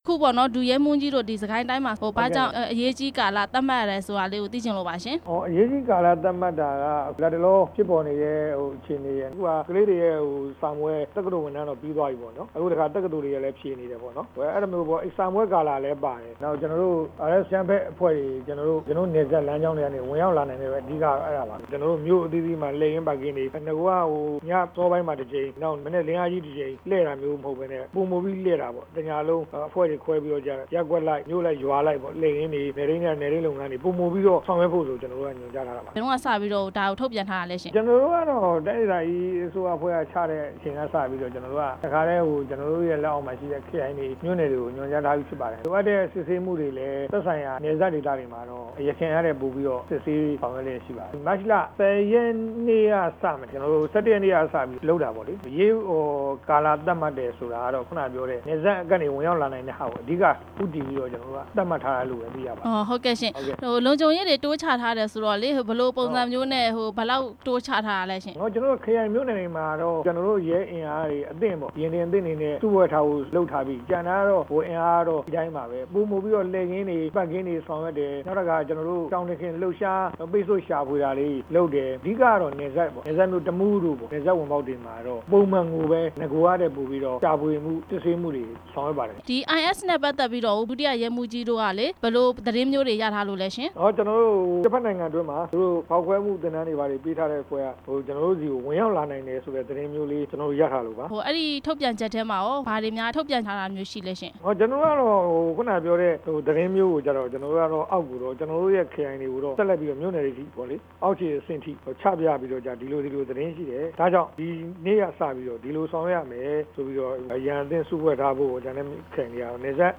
စစ်ကိုင်းတိုင်းဒေသကြီးမှာ လုံခြုံရေးတိုးမြှင့်ချထားတဲ့အကြောင်း မေးမြန်းချက်